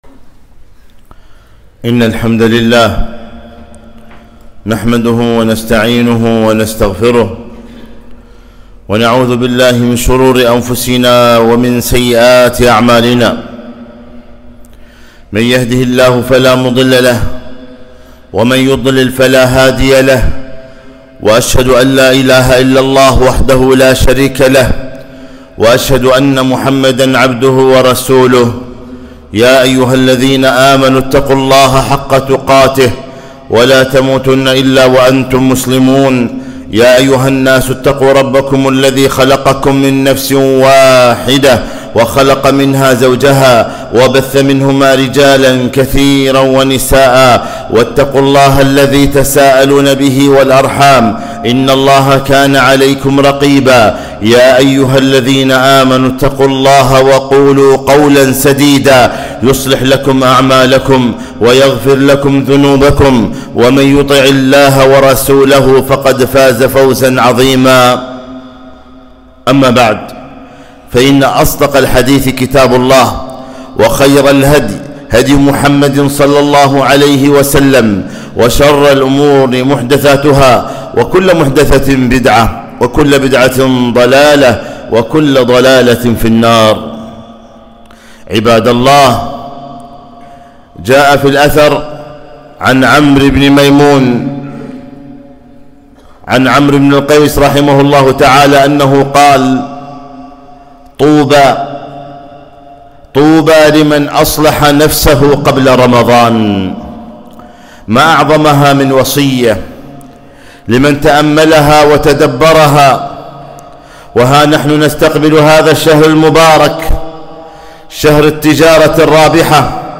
خطبة - طوبى لمن أصلح نفسه قبل رمضان